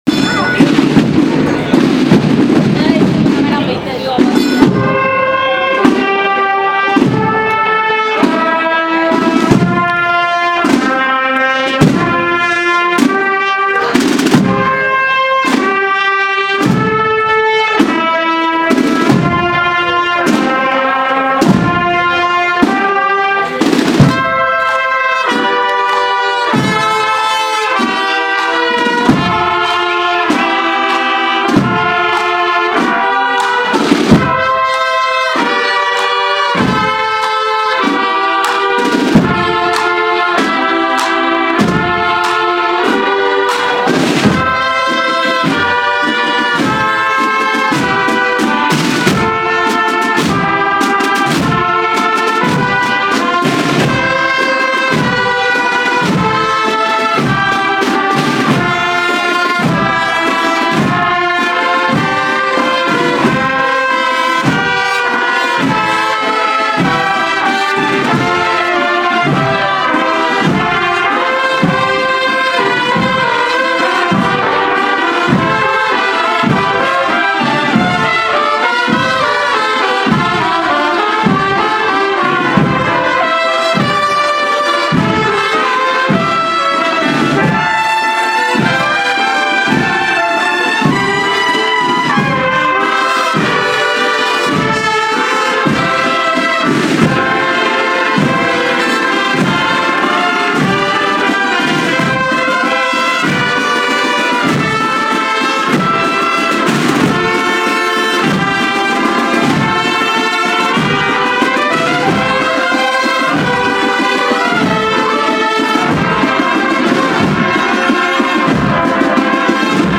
Se bendice la imagen de la Virgen del Calvario y el trono del conjunto escultórico de "El Lavatorio de Pilatos” de la hermandad de Jesús en el Calvario y Santa Cena
La imagen fue trasladada por los ayudas al templo de Santiago en el trono del “Lavatorio de Pilatos”, acompañada por la banda de cornetas y tambores de la hermandad.